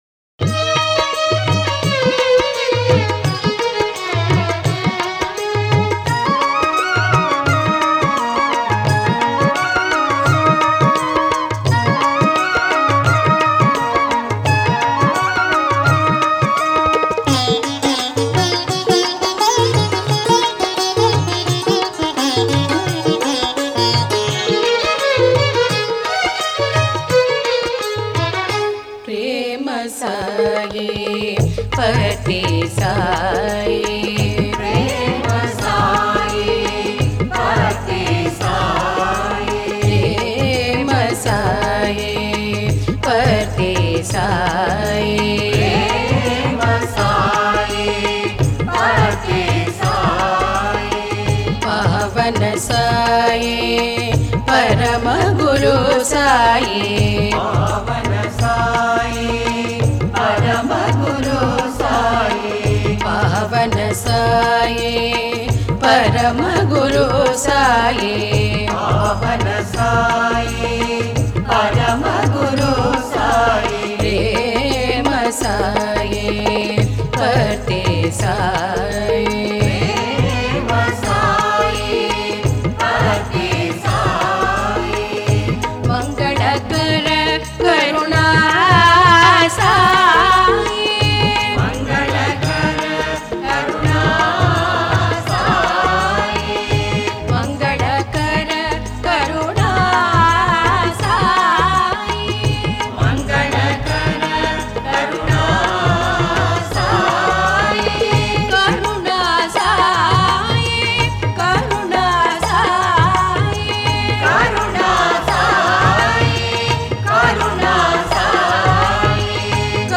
Author adminPosted on Categories Guru Bhajans